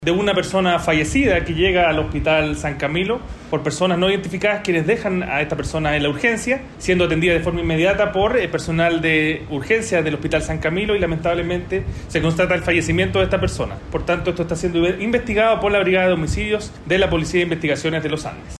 Por su parte, Daniel Muñoz, delegado presidencial provincial de San Felipe, señaló que personas que tampoco han sido identificadas dejaron a la víctima en la unidad de Urgencias.